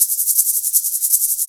Shaker 03.wav